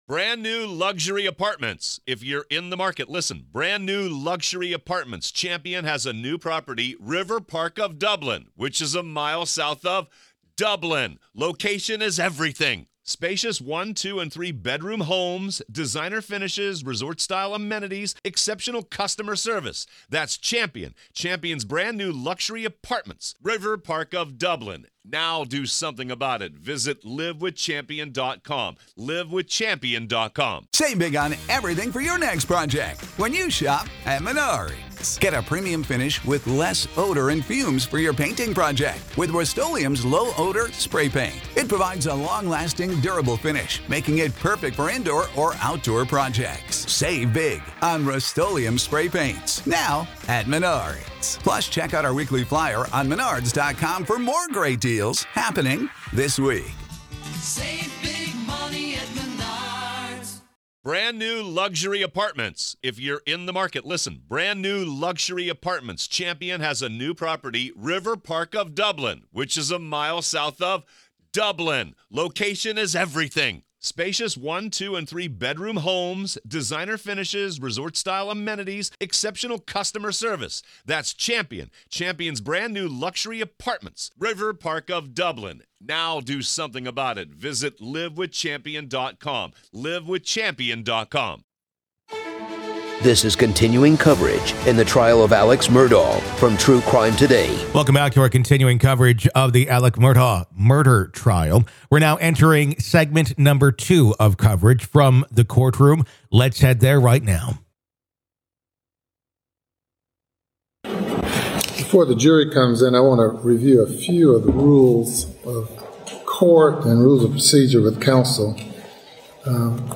Listen to the full courtroom trial coverage of Alex Murdaugh. This is our continuing coverage of the Alex Murdaugh murder trial.